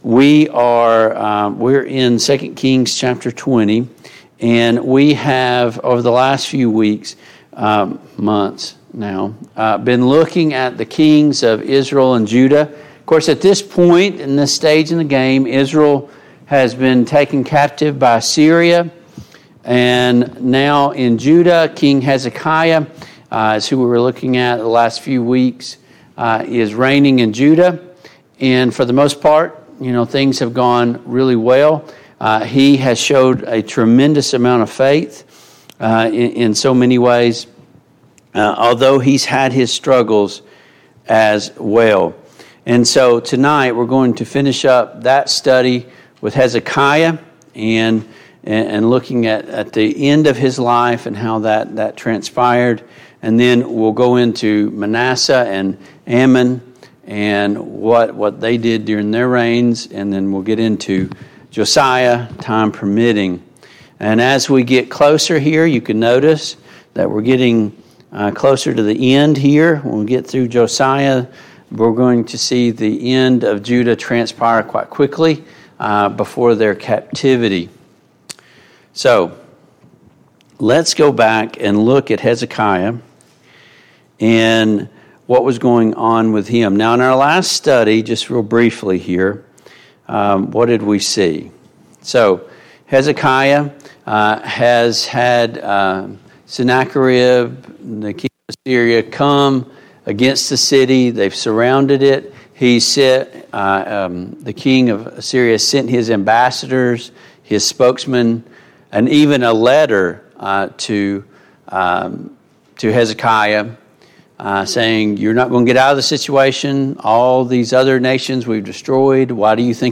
The Kings of Israel and Judah Service Type: Mid-Week Bible Study Download Files Notes « A Deeper Examination of Philemon 77.